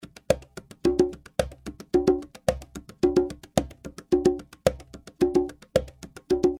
110 BPM conga loops part B (6 variations)
Real conga loops played by professional percussion player at 220 BPM.
The conga loops were recorded using 3 microphones,
(AKG C-12 VR , 2 x AKG 451B for room and stereo).
All the loops are dry with no reverb ,light EQ and compression, giving you the Opportunity to shape the conga loops in your own style and effect. The conga loops are for salsa beat, will work great If you are composing a latin salsa song.
*- room reverb was added to the conga loops in the preview.